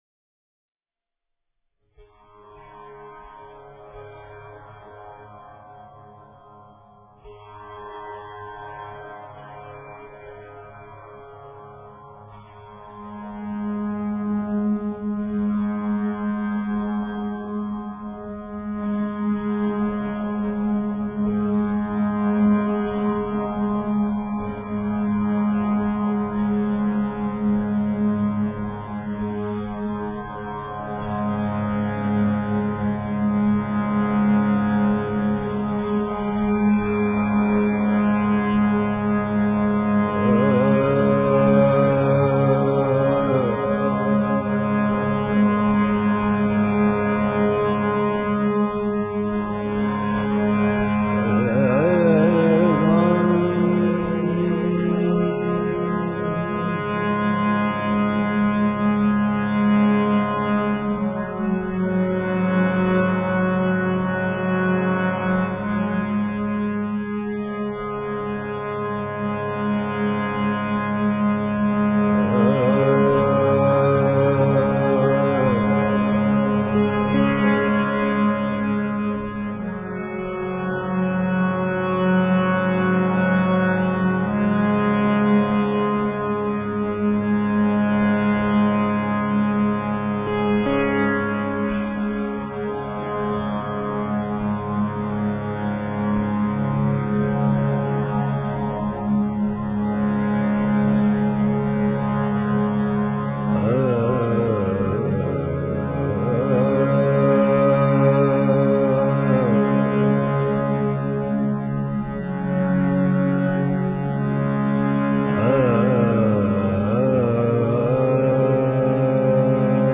恒河冥想 诵经 恒河冥想--瑜伽 点我： 标签: 佛音 诵经 佛教音乐 返回列表 上一篇： 般若波罗蜜多心经 下一篇： 绿度母心咒 相关文章 貧僧有話21說：我的管理模式--释星云 貧僧有話21說：我的管理模式--释星云...